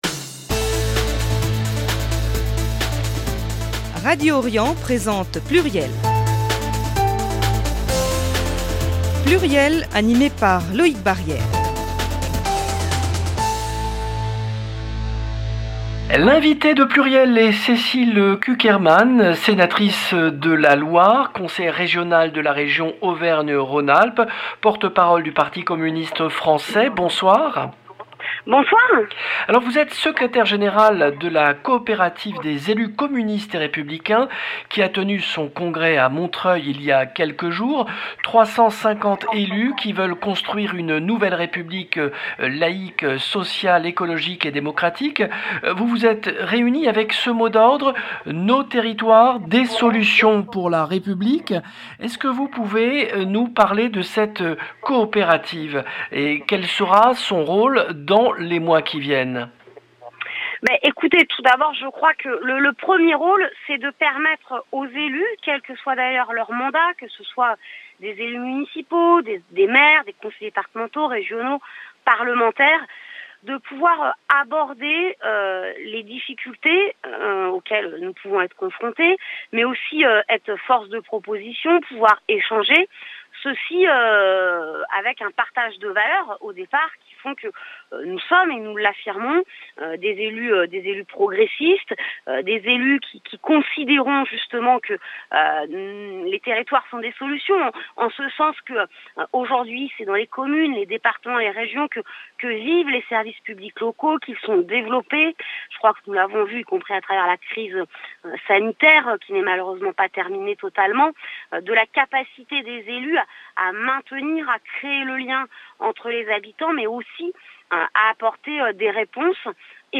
Cécile Cukierman, sénatrice et porte-parole du PCF